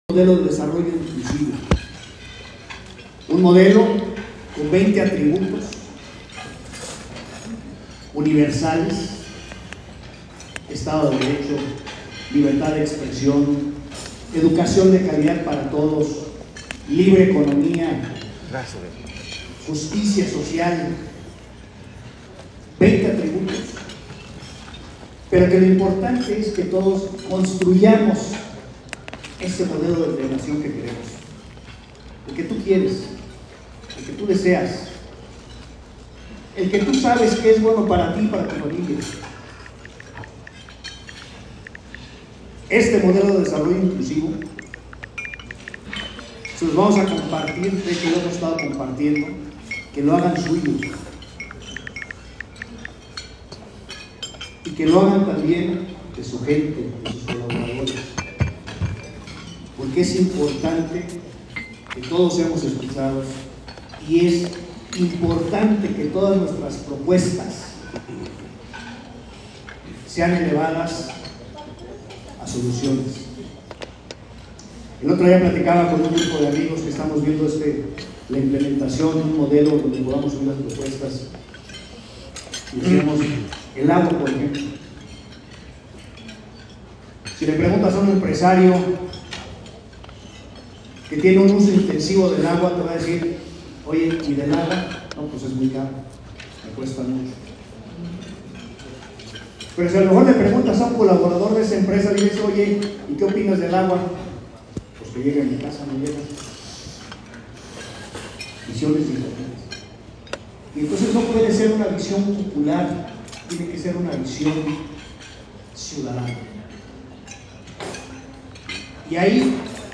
Fue enfática su alocución en poner el dedo en la llaga ante los embates del oficialismo, el cual ha mantenido una posición rijosa y en donde la sociedad civil se queda callada.